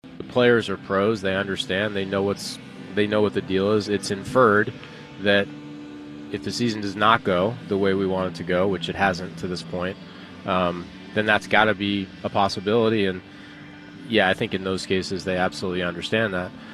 Cherington says none of the Pirates have asked to be traded to a contender, but they’ve all been aware a trade could happen, especially those whose contracts are expiring after this season.